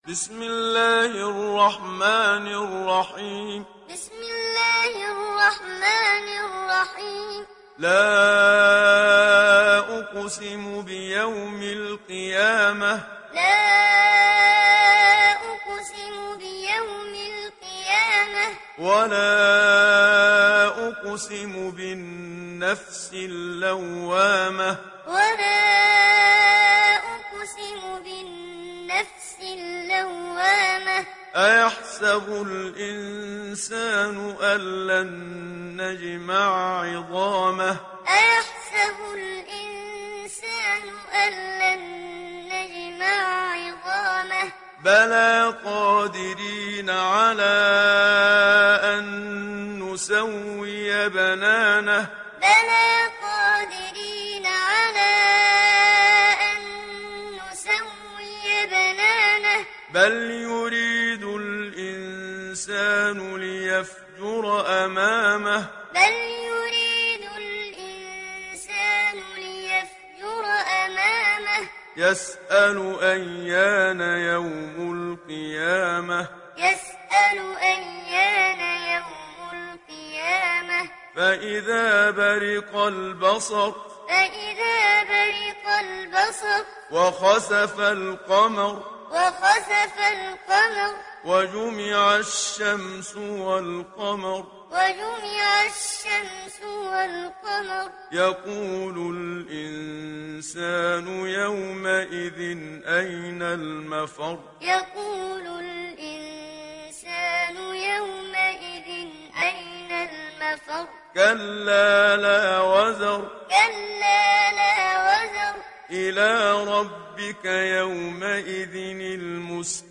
İndir Kiyame Suresi Muhammad Siddiq Minshawi Muallim